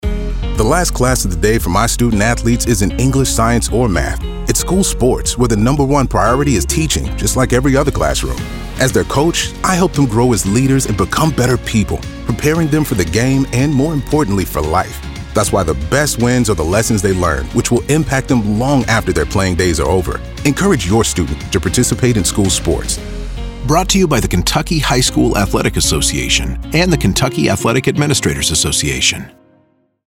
25-26 Radio – Public Service Announcements